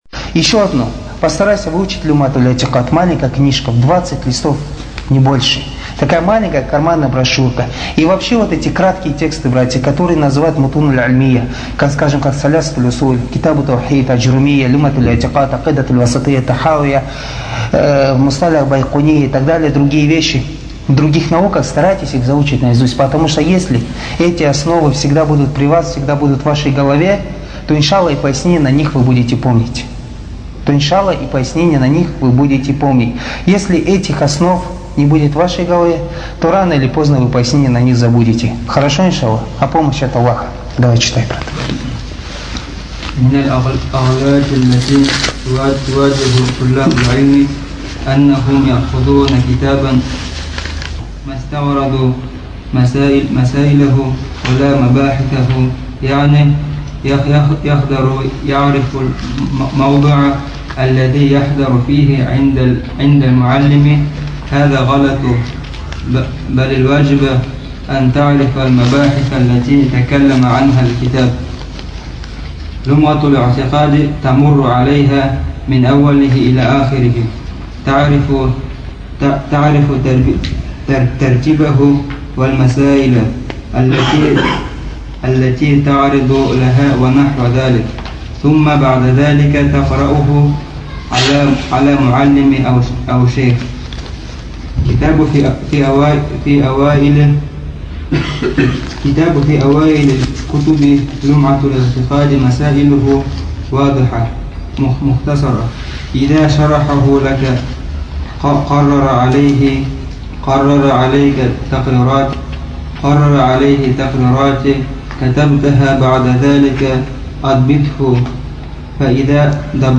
Лекция